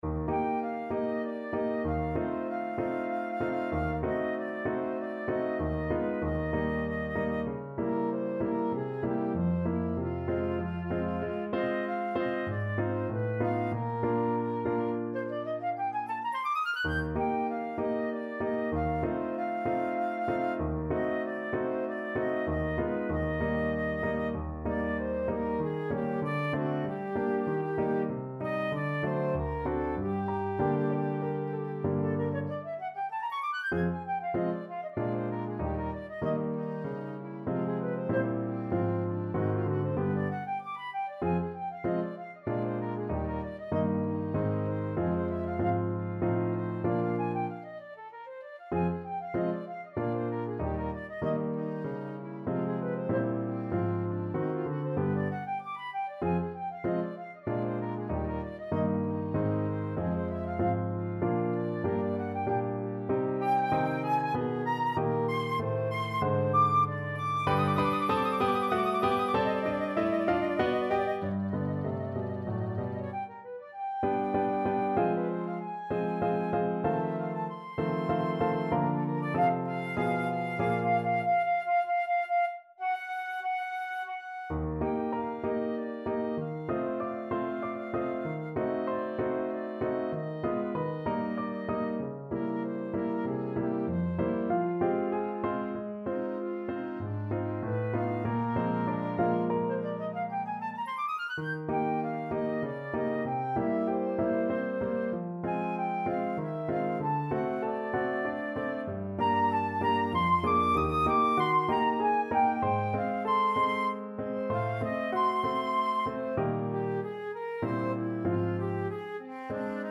has been beautifully arranged for flute and piano.